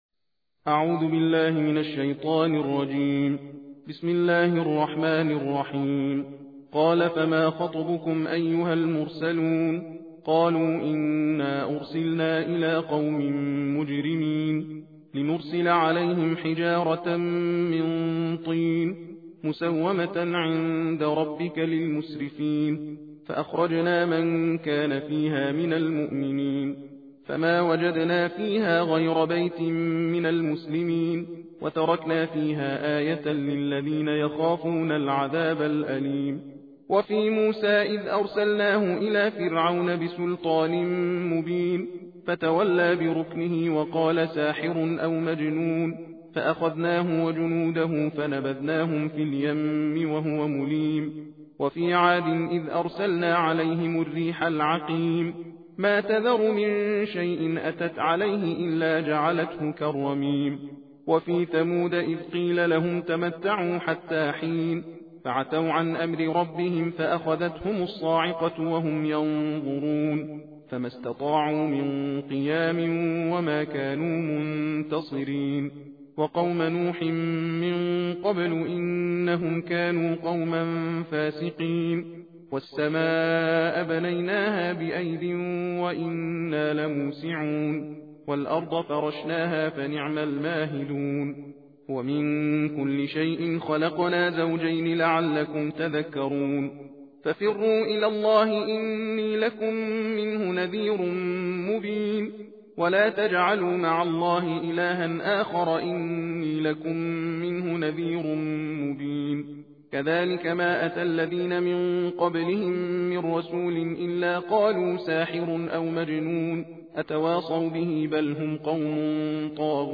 تندخوانی جزء بیست و هفتم قرآن کریم